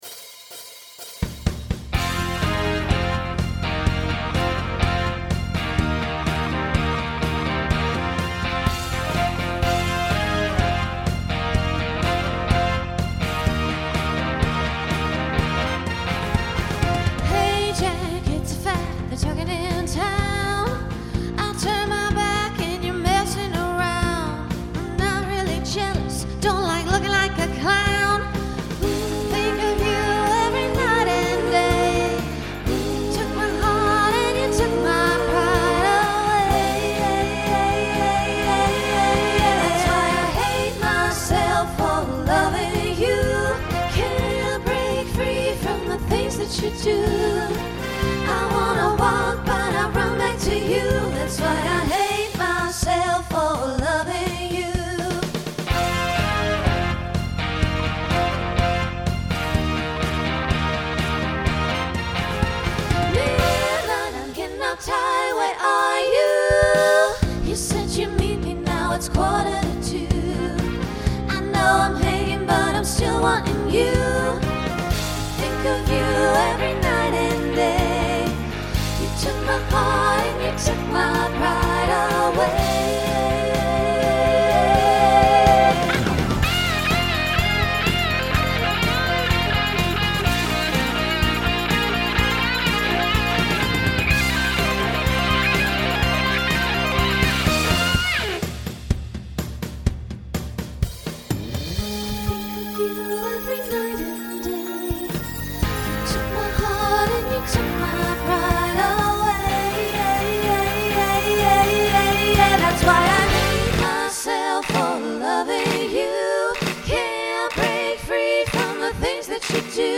Voicing SSA Instrumental combo Genre Rock